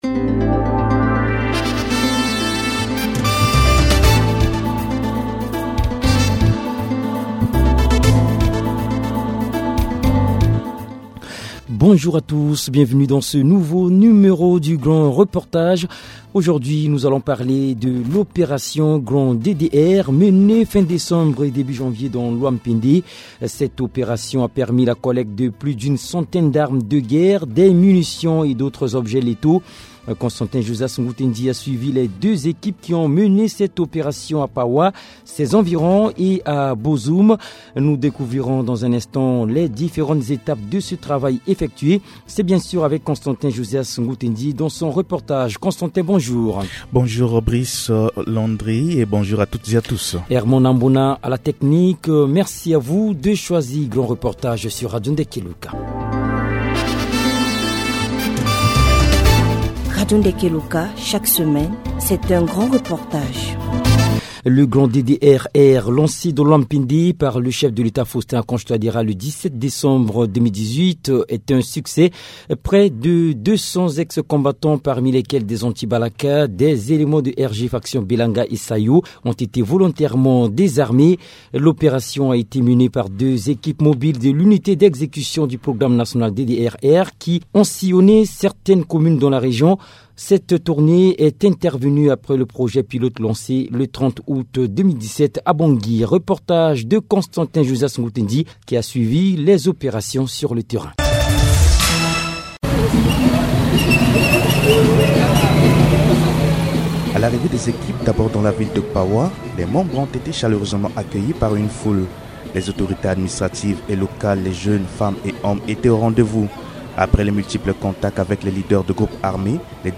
grand reportage pour découvrir le fort moment de ce processus crucial pour le retour de la paix en Centrafrique.